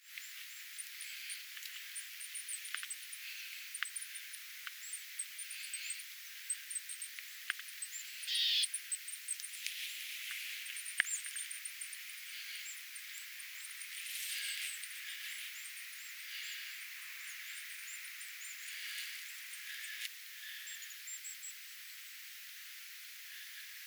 vähän hippiäisten ääntelyä
vahan_hippiaisten_aantelya.mp3